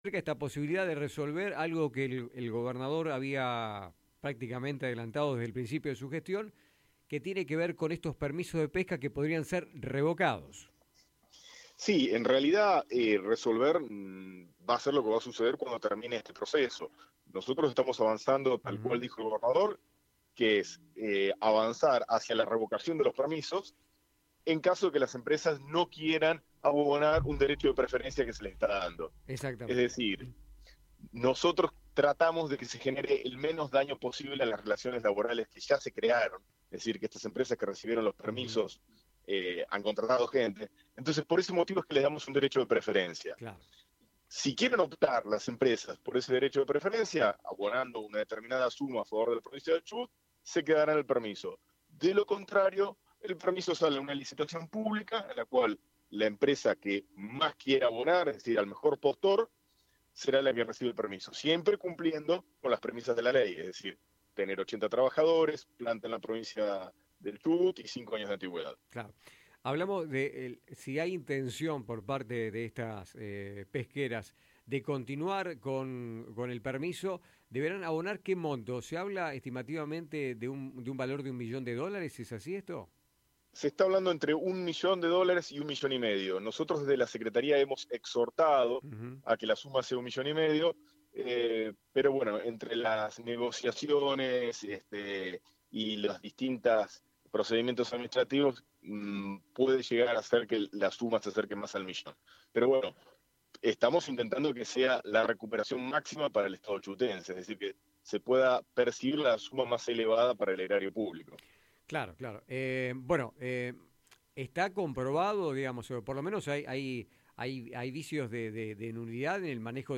En una entrevista con Radio 3, Andrés Arbeleche, Secretario de Pesca de la Provincia de Chubut, detalló el proceso para revocar los permisos de pesca otorgados a empresas que no cumplan con ciertas condiciones.